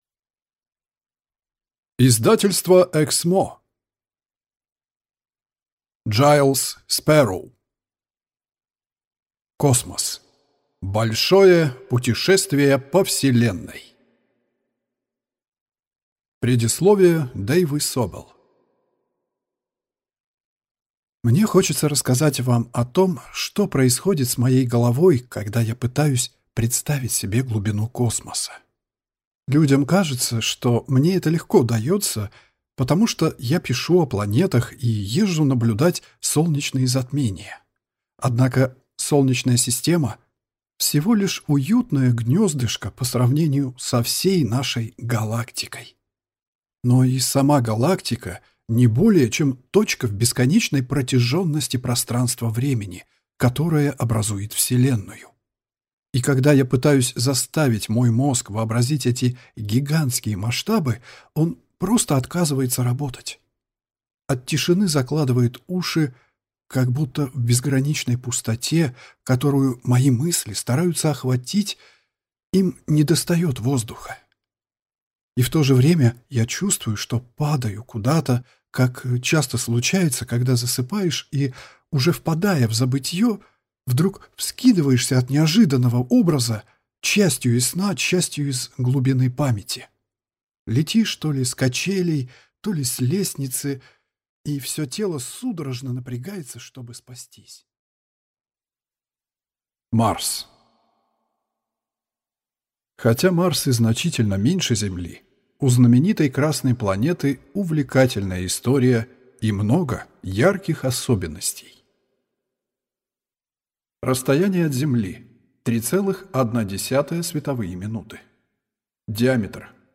Аудиокнига Космос. Большое путешествие по Вселенной | Библиотека аудиокниг